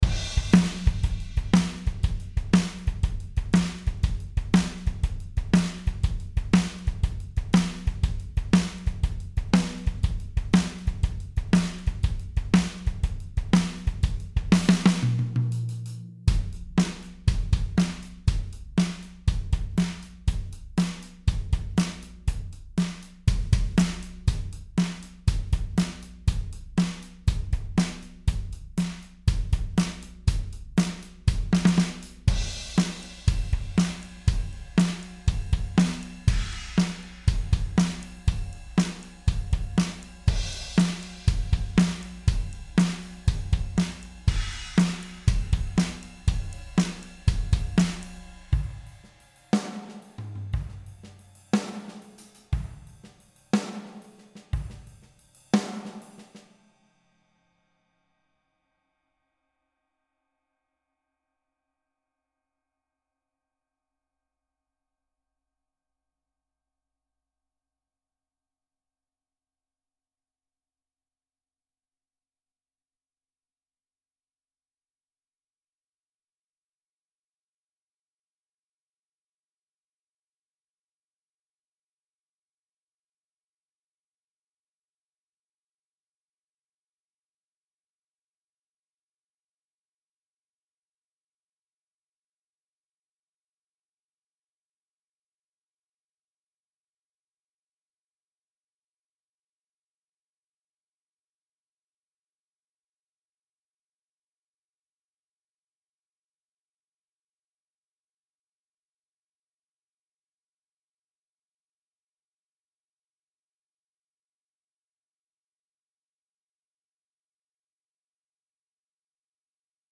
Das Drumasonic Bundle enthält Drumasonic 2 und Drumasonic Luxury, quasi 2 Schlagzeug Software Pakete in einem. Aufbauend auf Drumasonic 2 bietet die Luxury Variante 3 neue Bass Drums, 2 Snare Drums, 5 Toms, 3 HiHats, 2 Rides und 4 Crashes.